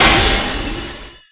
破碎.wav